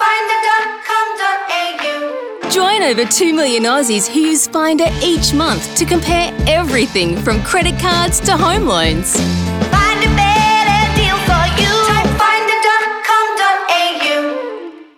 Home / Work / Voiceover / Finder